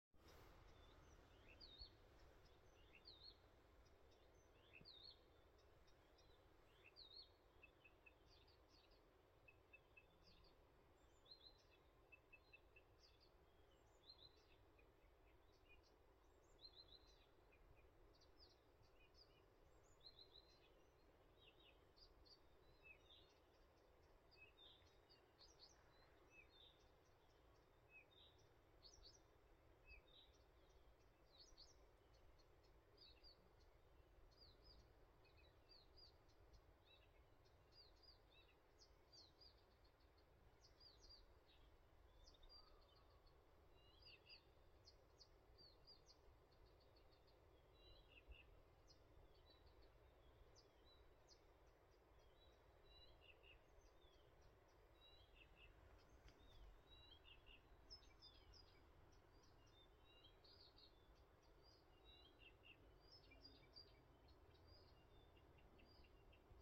Krūmu ķauķis, Acrocephalus dumetorum
StatussDzied ligzdošanai piemērotā biotopā (D)
Piezīmes/Šovakar skanīga nakts. Vismaz 3 Krūmu Ķauķi dzirdami no dažādām pusēm, bet vai šis audio arī ir Krūmu Ķauķis? izklausās citādāk.